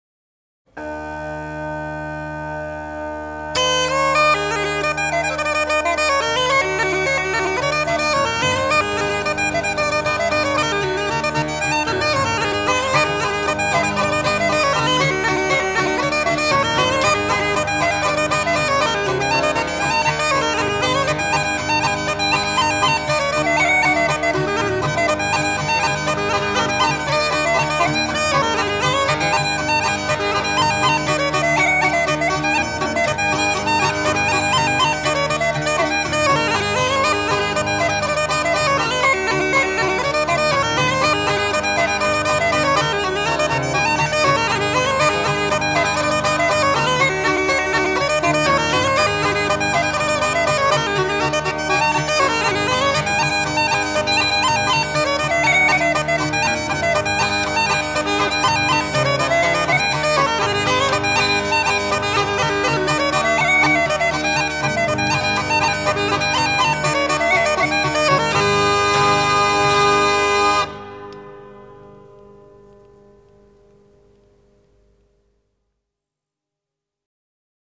And so we come to what should have been my initial thought on how to celebrate St. Patrick’s Day – with real Irish music.
Then we’ll have Finbar & Eddie Fury perform the reel “Pigeon On The Gate”